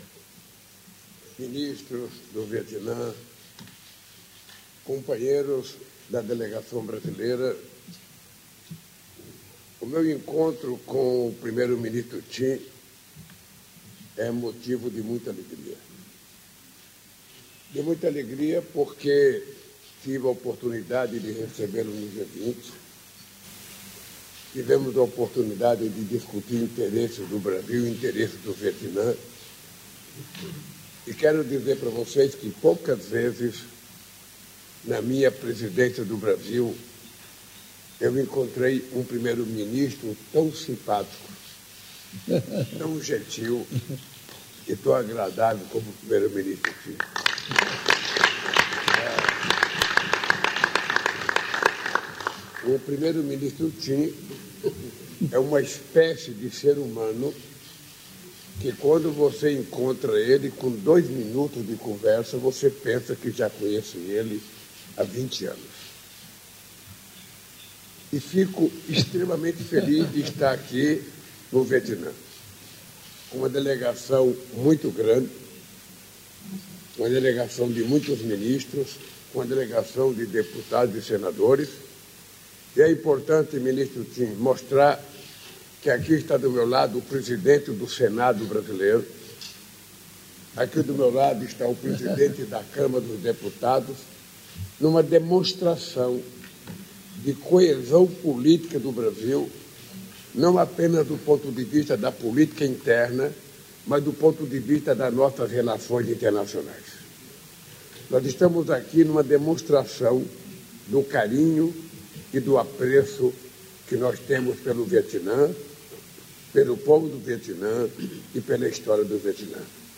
Íntegra da entrevista coletiva do presidente da República, Luiz Inácio Lula da Silva, após participação no Seminário Empresarial Brasil - Vietnã, neste sábado (29), em Hanói - Vietnã.